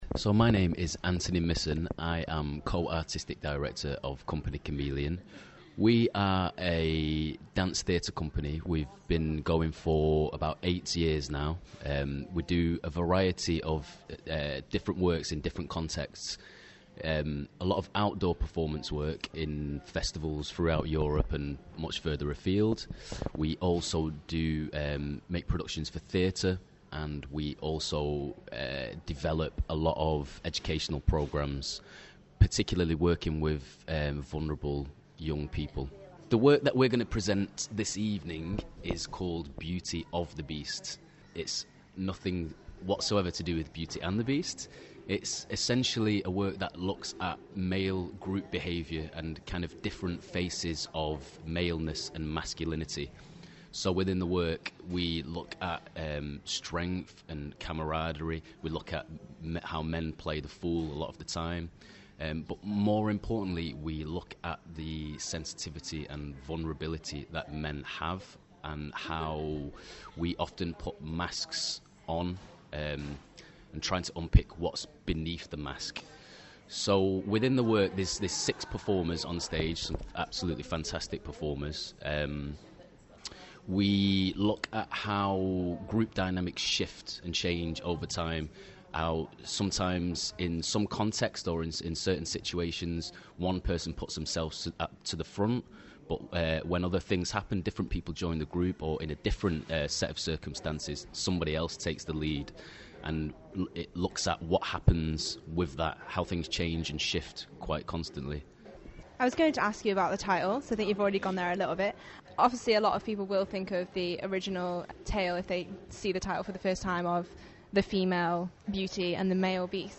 The Arts Show: Interview